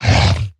Minecraft Version Minecraft Version 1.21.5 Latest Release | Latest Snapshot 1.21.5 / assets / minecraft / sounds / mob / zoglin / attack1.ogg Compare With Compare With Latest Release | Latest Snapshot
attack1.ogg